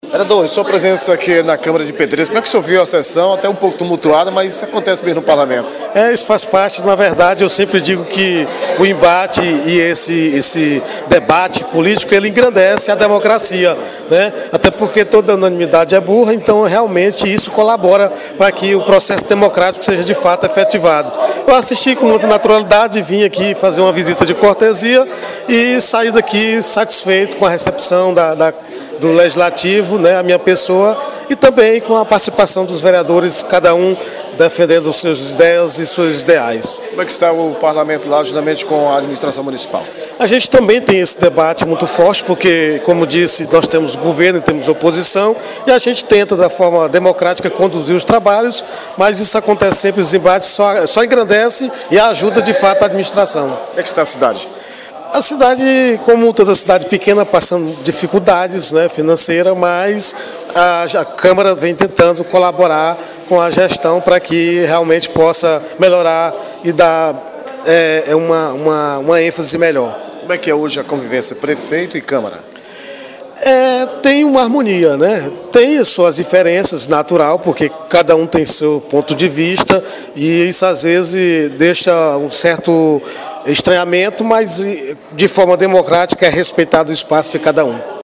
Valney Gomes de Oliveira, (PPS), Presidente da câmara de Poção de Pedras, participou da sessão, como convidado especial. Ao deixar o plenário, avaliou a discussão dos vereadores em torno da CPI.